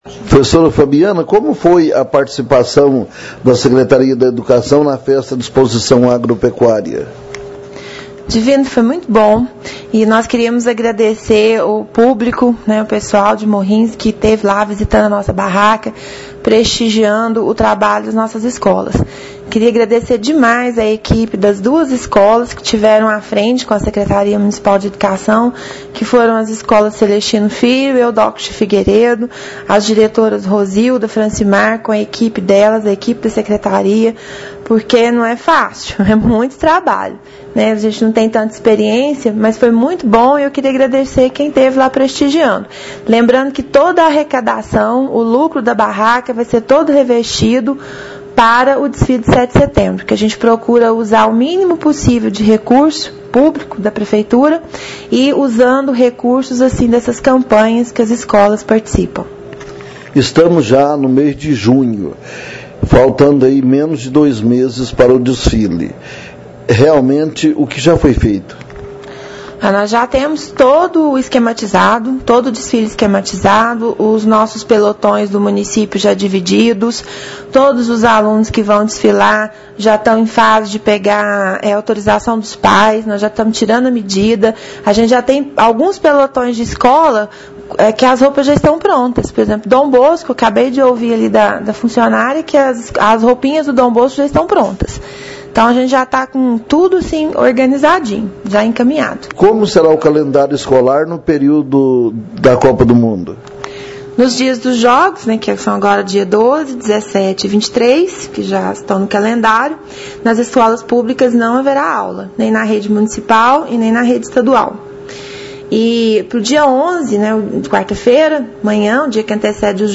Ouça a entrevista da  Secretária.
Fabiana.mp3